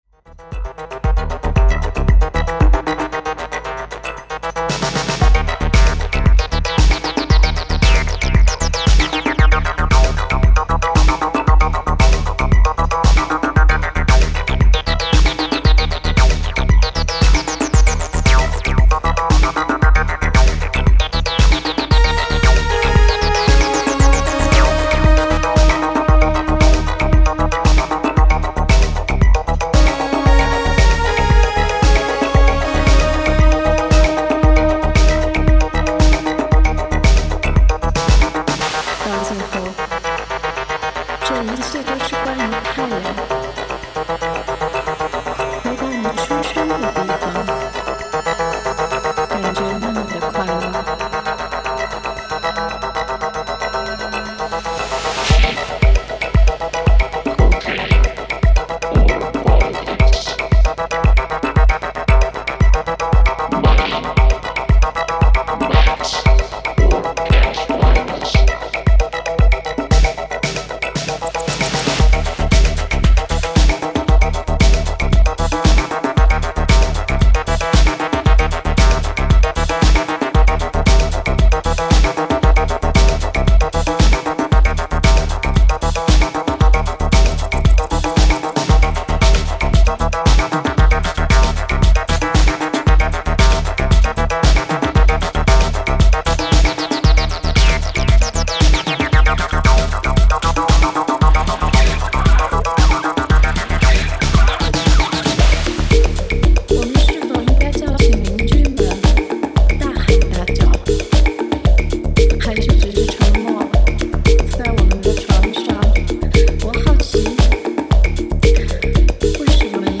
G-funk meets electro on the flipside
Disco House